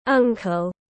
Chú bác tiếng anh gọi là uncle, phiên âm tiếng anh đọc là /ˈʌŋ.kəl/.